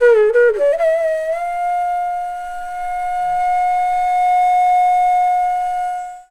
FLUTE-A10 -R.wav